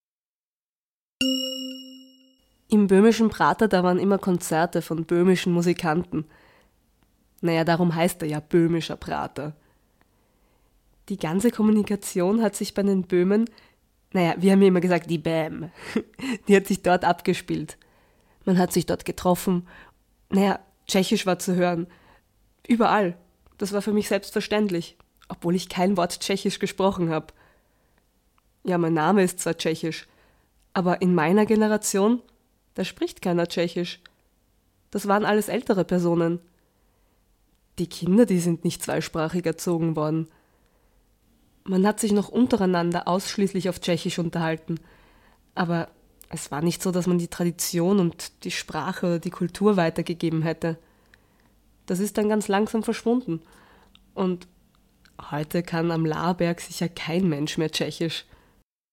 Für die Website wurden die Textstellen nachgelesen: Einige von Jugendlichen und jungen Erwachsenen aus den Wohnhausanlagen, andere von Mitgliedern des Projektteams oder ausgebildeten Schauspielern.